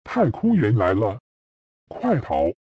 步驟2：接著在方框內輸入文字，按一下播放按鈕，成功將文字轉語音！